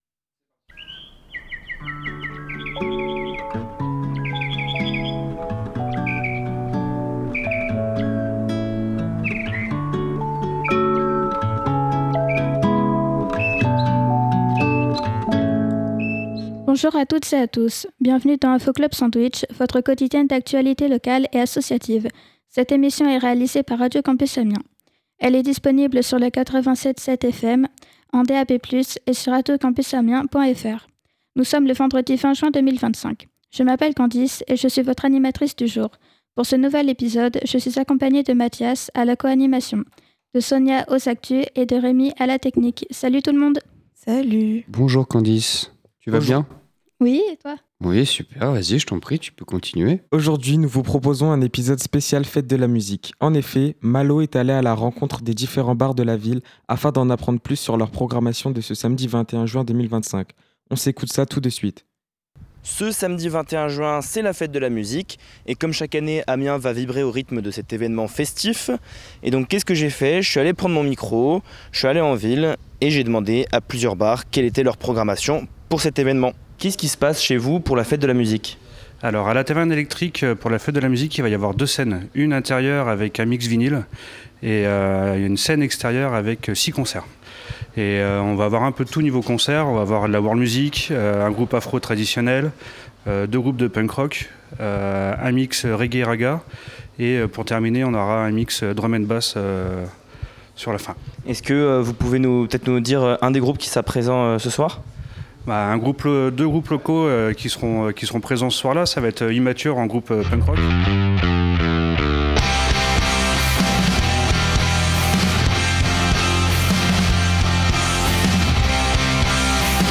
Aujourd’hui, différents barmans de la ville nous parlent de ce qu'ils ont prévus pour le week-end de clôture du festival des Rendez-Vous de la BD d'Amiens.